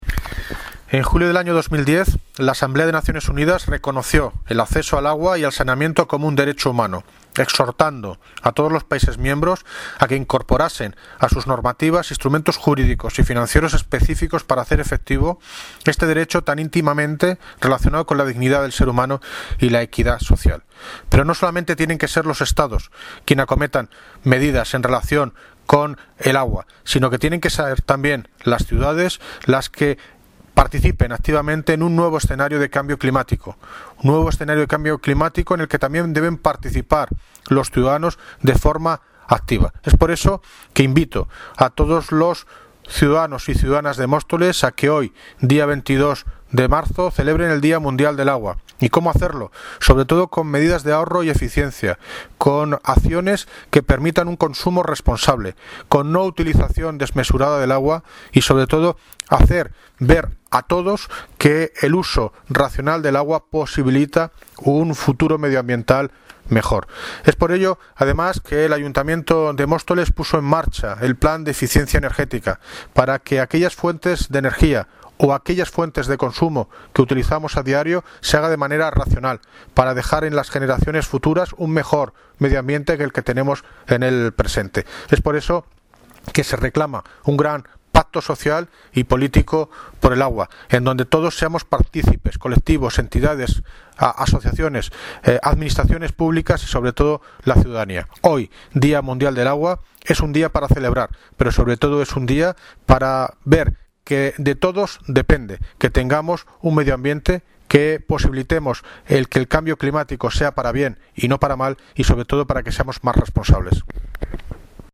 Audio - David Lucas (Alcalde de Móstoles) Sobre Día Internacional del Agua